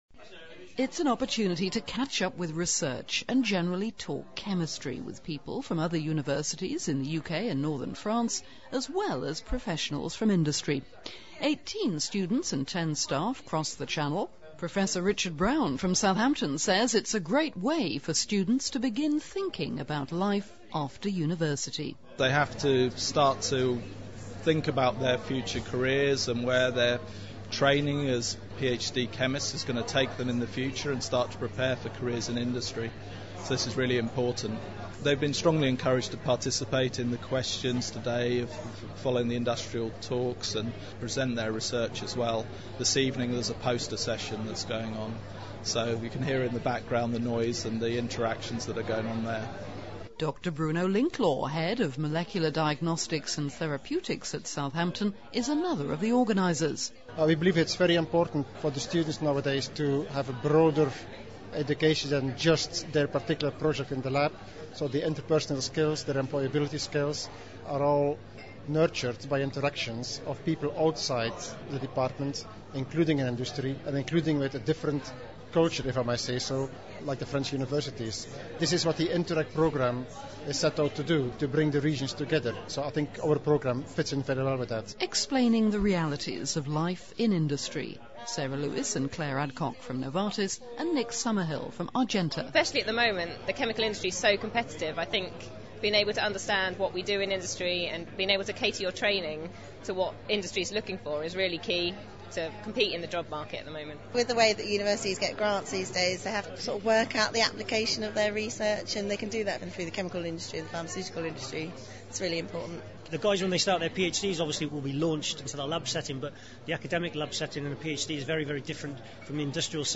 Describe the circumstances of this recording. A-I Chem Channel Consortium European Union’s InterReg Useful Downloads Audio podcast from the event Interreg spring school_mixdown - mono 32.mp3